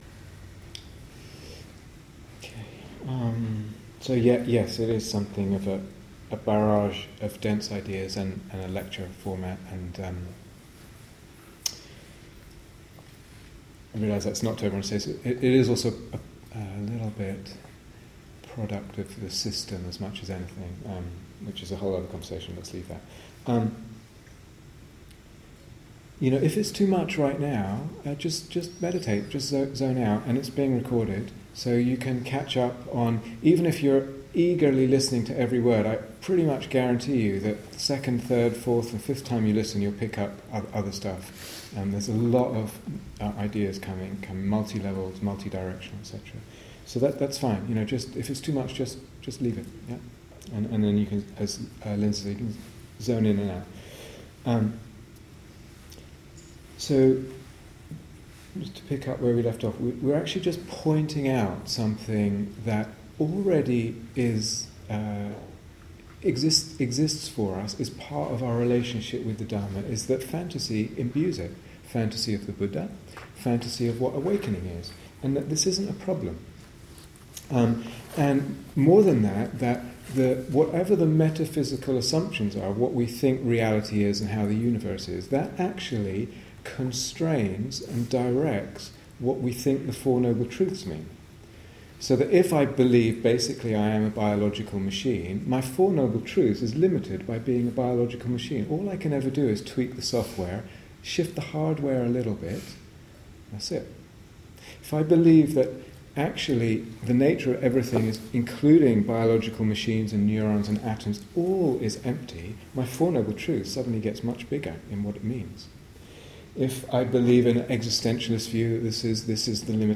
Image, Mythos, Dharma (Part Four) Download 0:00:00 --:-- Date 6th December 2014 Retreat/Series Day Retreat, Bodhi Tree Brighton Transcription So yes, it is something of a barrage of dense ideas and a lecture format.